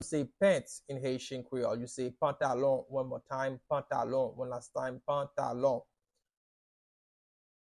Pronunciation:
How-to-say-Pants-in-Haitian-Creole-Pantalon-pronunciation-by-a-Haitian-teacher.mp3